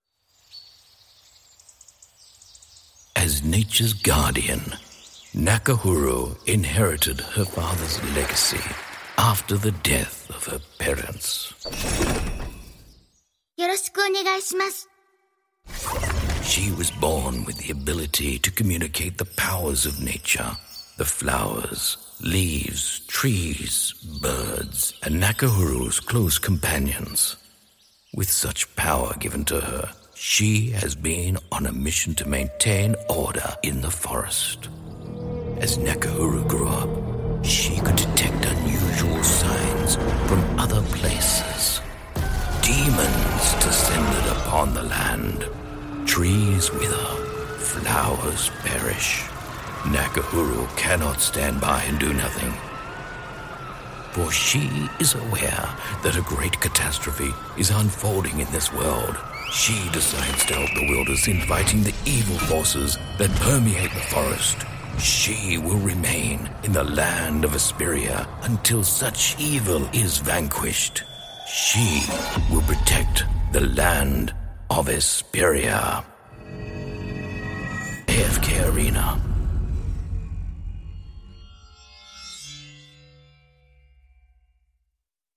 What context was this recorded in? Neumann TLM 103 mic